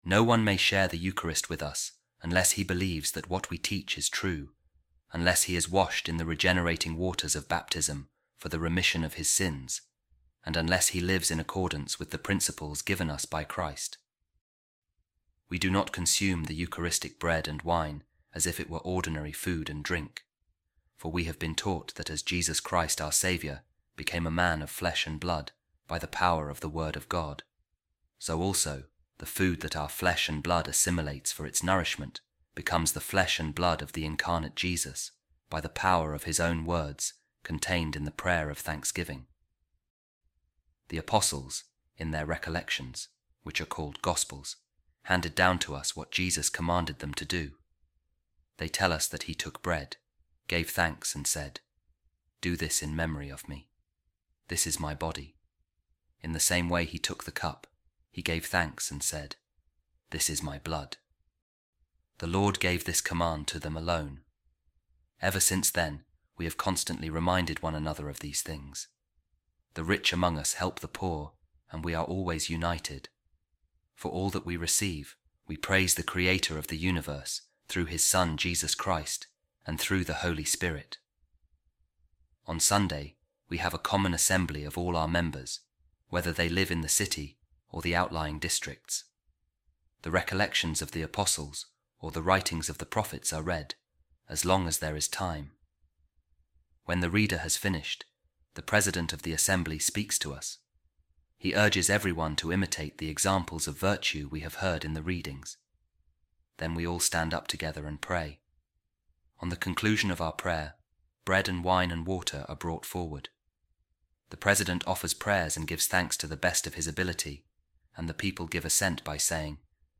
Divine Office | Office Of Readings